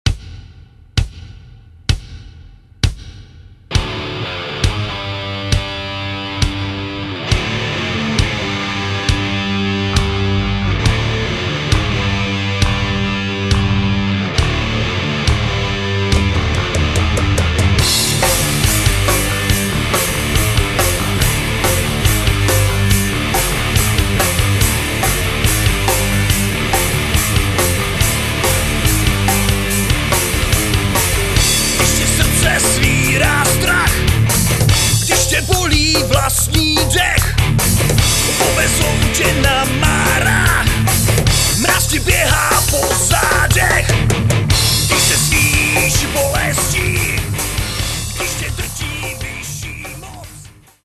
zpěv
kytara
bicí